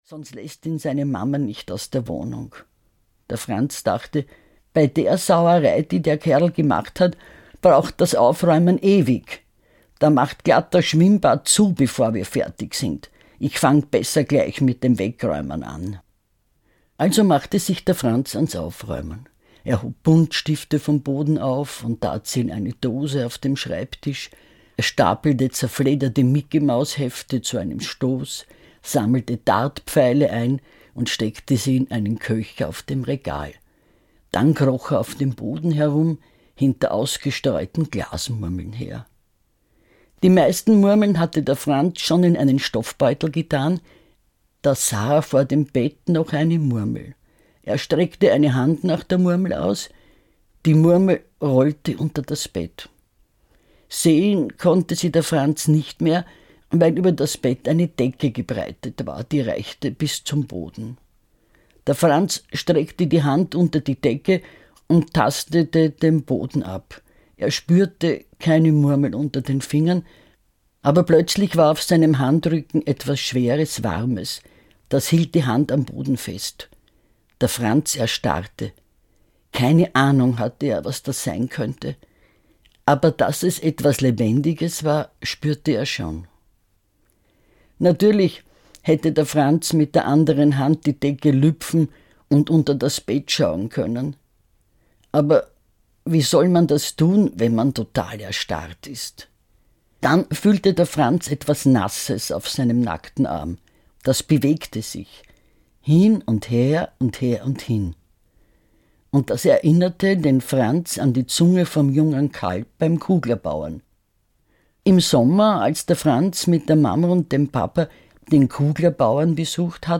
Hörbuch Hundegeschichten vom Franz, Christine Nöstlinger.